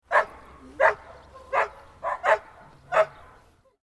Intense Dog Barking Sound Effect
Fierce guard dog barking at strangers outside. Aggressive watchdog barking at passersby. Village ambience.
Intense-dog-barking-sound-effect.mp3